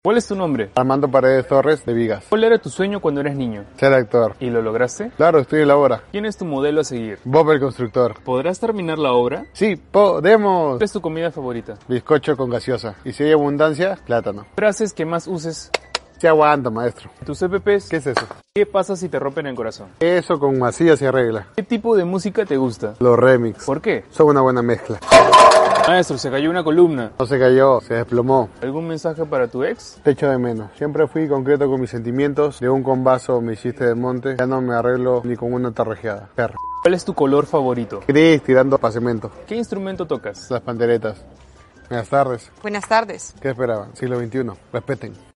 Entrevista A Un Albañil 💪🏼 Sound Effects Free Download